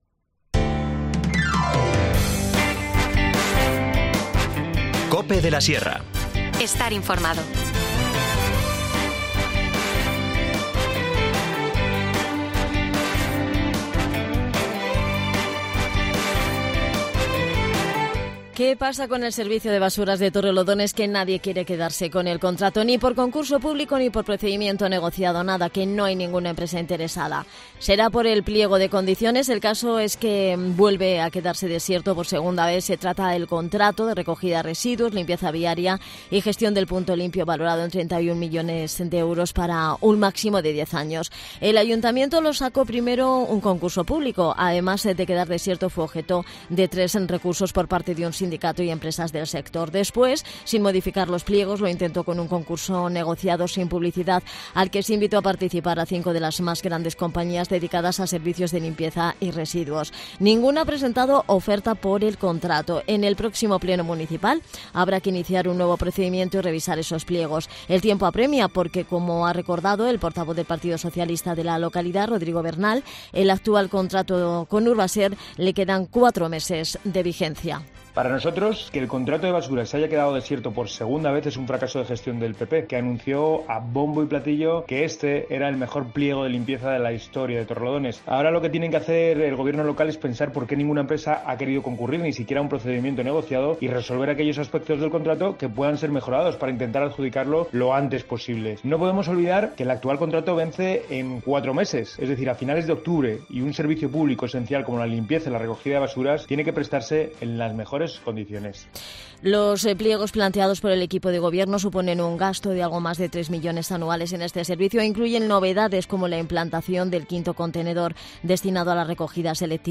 Charlamos con Lourdes Cuesta, concejal de Cultura.